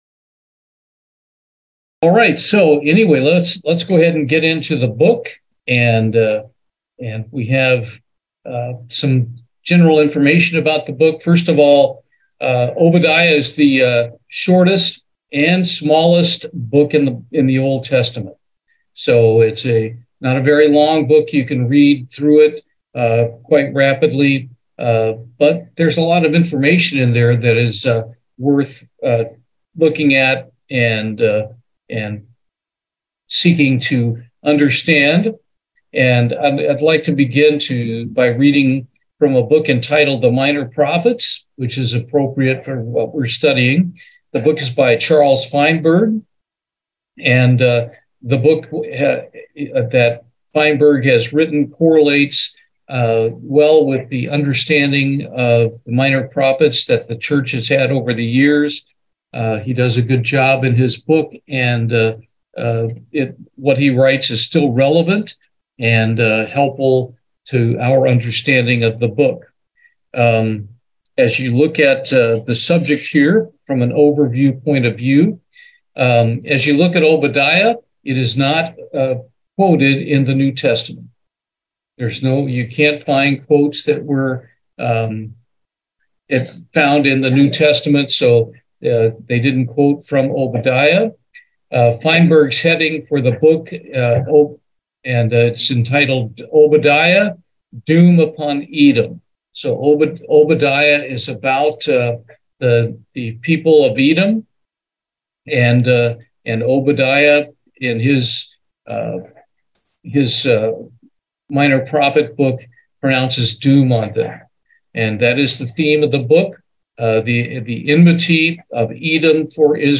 Bible Study, Obadiah
Given in Houston, TX